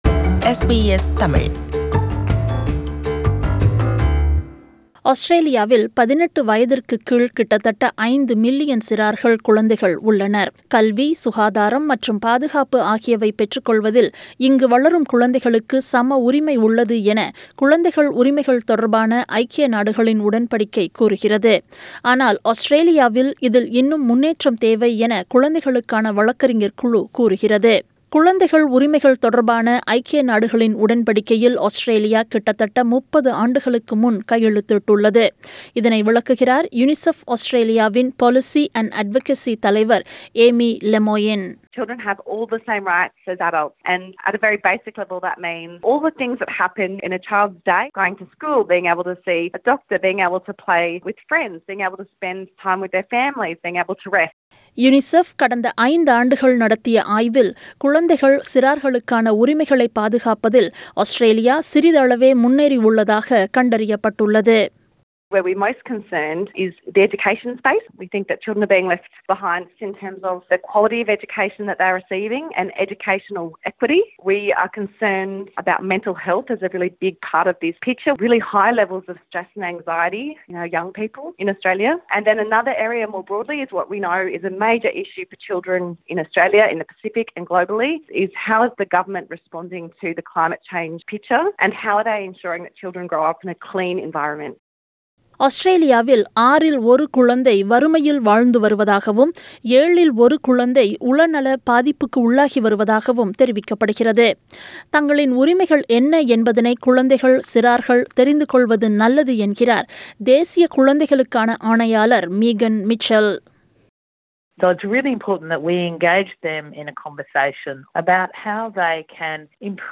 விவரணம்